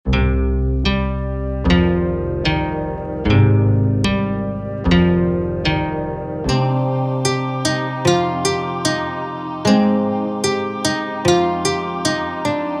Mit diesem Effekt kannst Du deinen Beat verlangsamen, zum Stottern bringen oder verrückte Zeitverzerrungen gestalten, die das Zeit-Raum-Kontinuum in Verlegenheit bringen.
Das Plugin nimmt immer einen Teil deines Beats auf und gibt ihn dir verdreht wieder zurück.